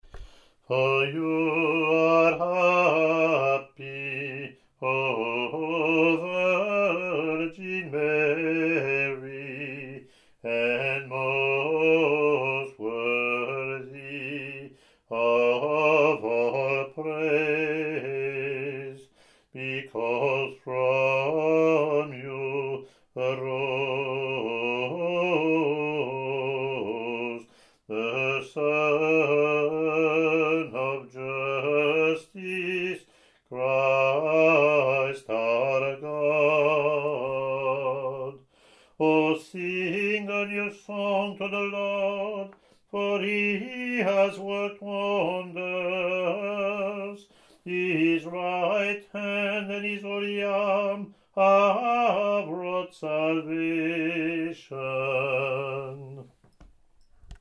English antiphon – English verse